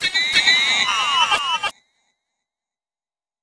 Techno / Voice